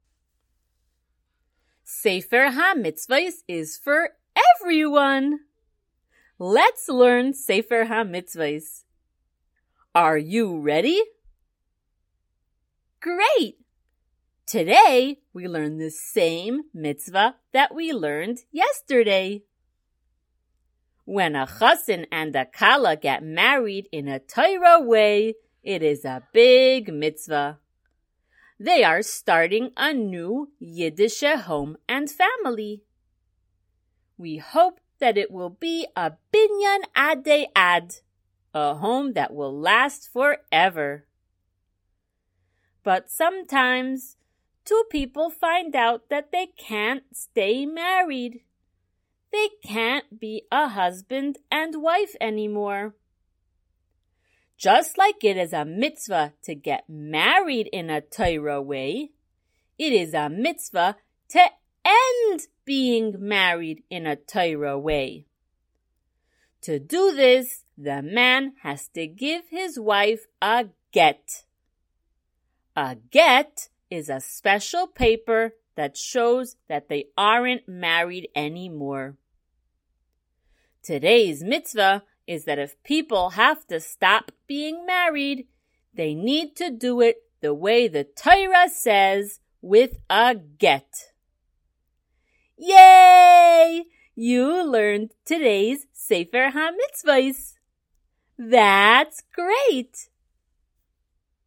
Color Shiur #79!
SmallChildren_Shiur079.mp3